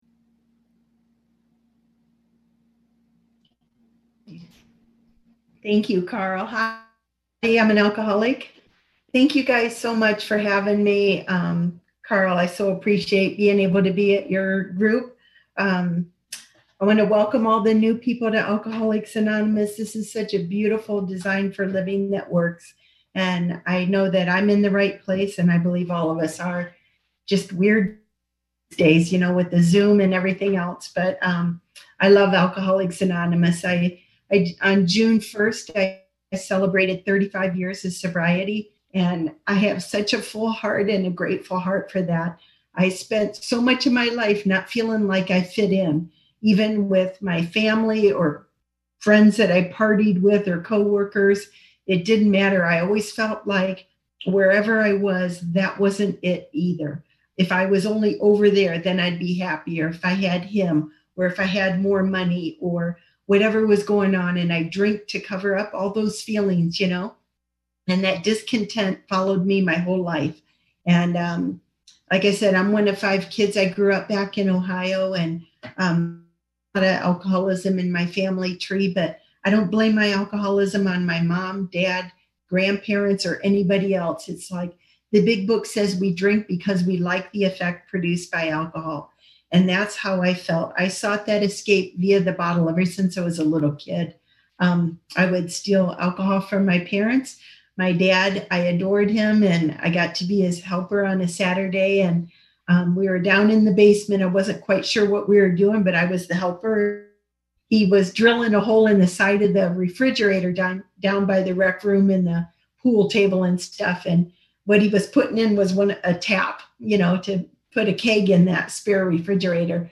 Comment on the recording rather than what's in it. Covina Speaker Meeting